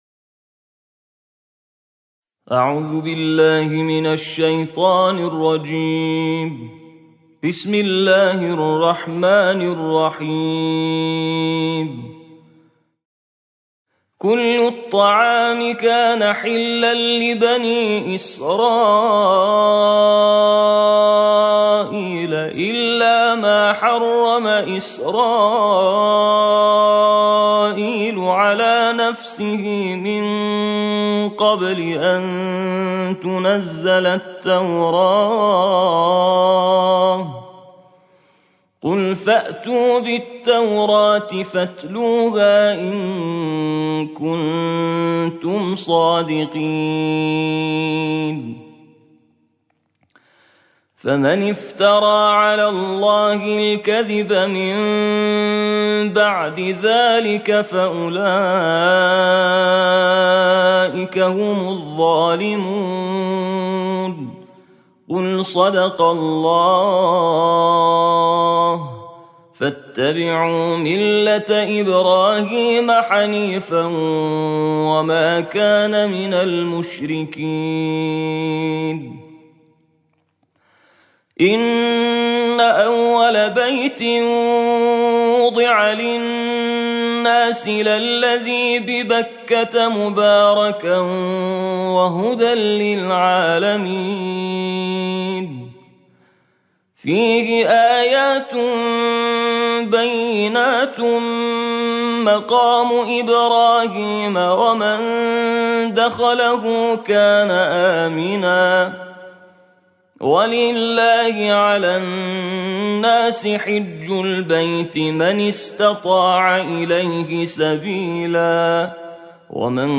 tilavet